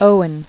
[Ow·en]